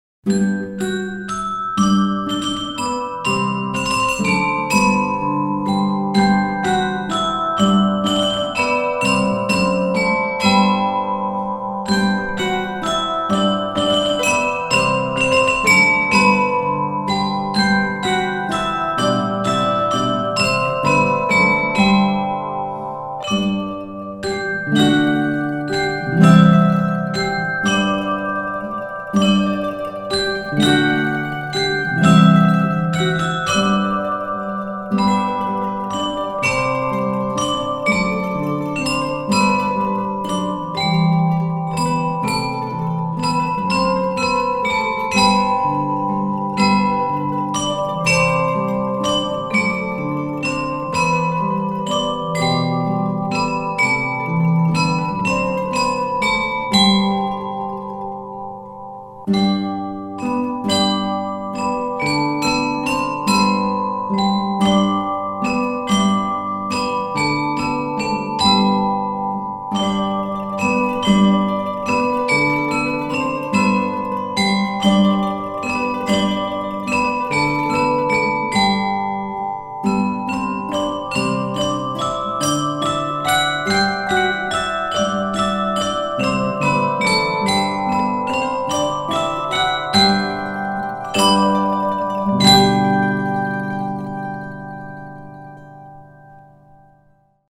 Il suono delle campanine – Le scuole campanarie della FCB
Esegue la Scuola Campanaria di Roncobello.